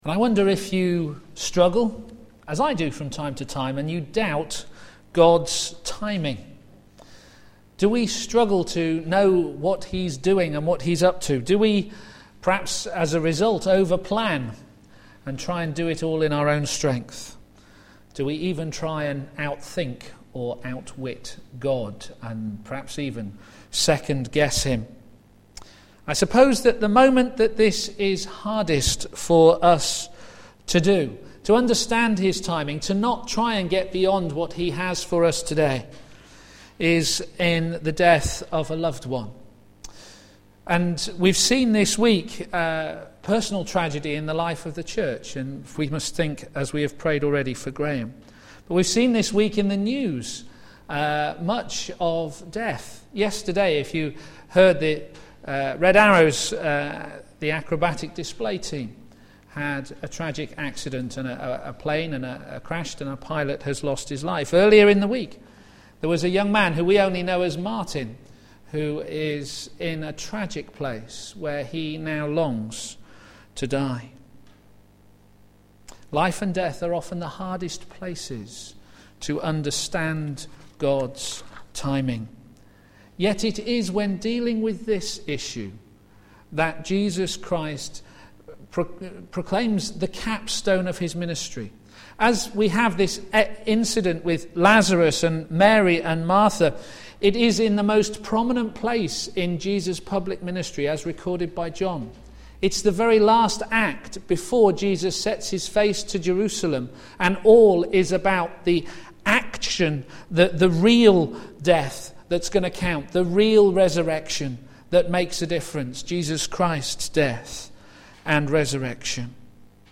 Media Library Media for a.m. Service on Sun 21st Aug 2011 10:30 Speaker
Theme: Jesus Said - I Am the Resurrection and the Life Sermon